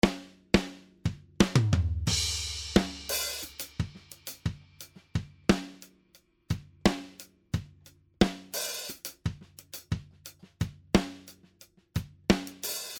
Ich habe Ratio 4:1 und Attack/Release auf kürzeste Zeit eingestellt.